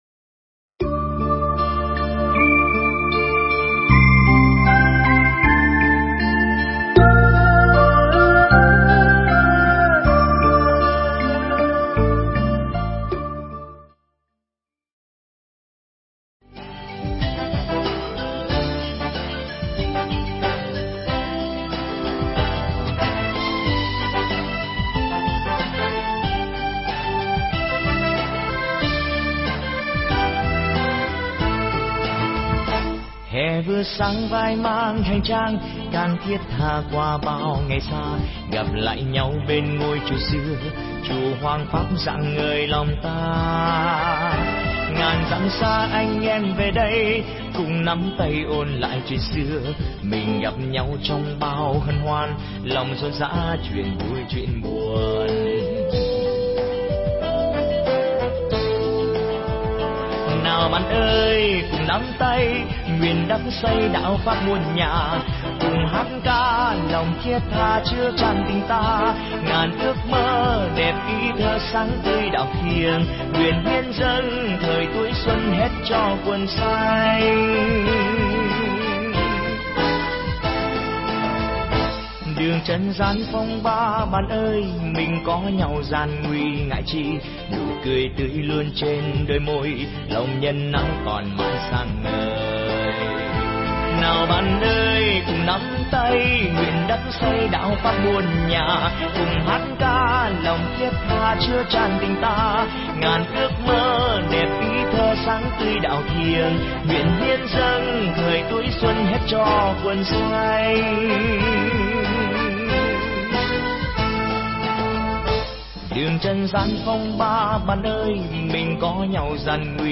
Mp3 thuyết pháp